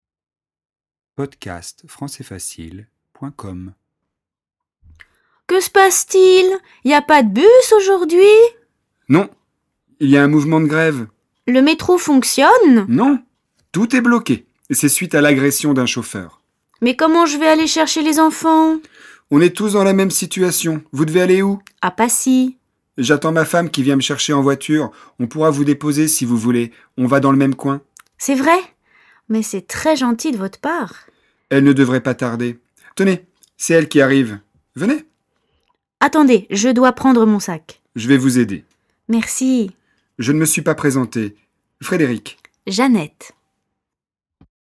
🎧  DIALOGUE :